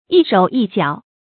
一手一腳 注音： ㄧ ㄕㄡˇ ㄧ ㄐㄧㄠˇ 讀音讀法： 意思解釋： 見「一手一足」。